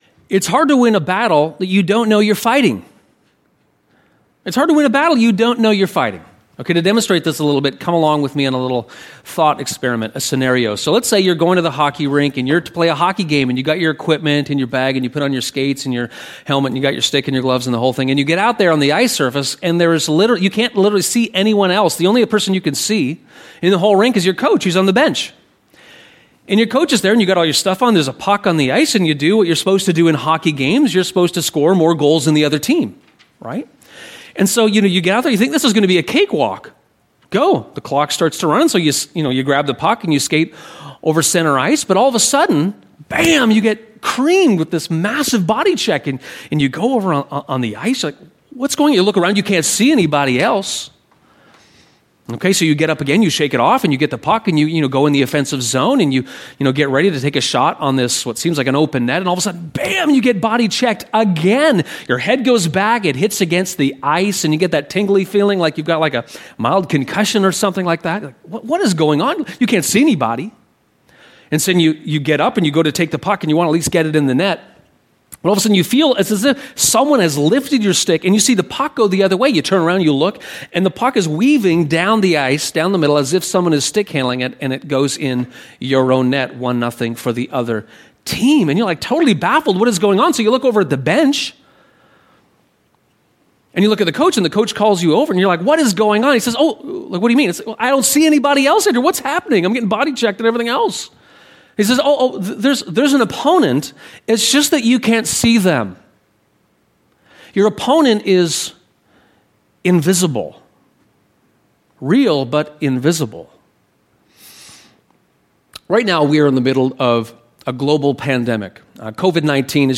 Your weapons in the war against fear [Sermon]